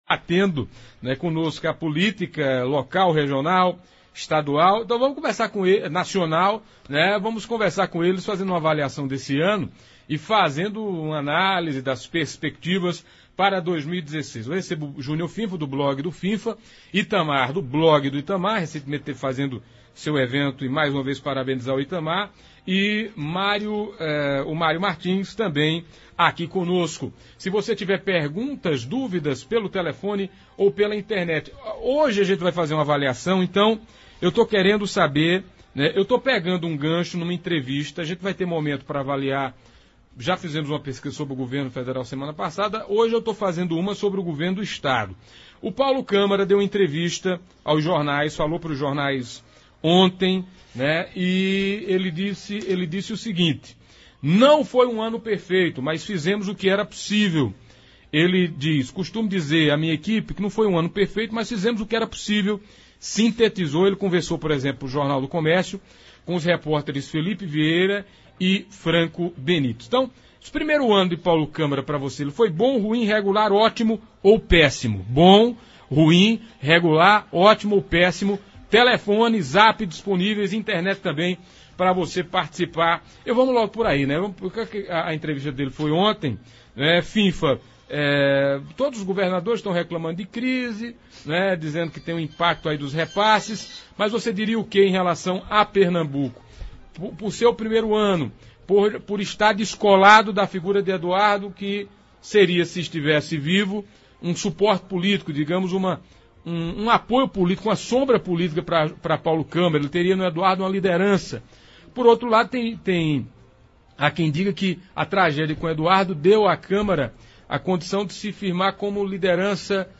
Ouça abaixo na íntegra como foi o debate de hoje: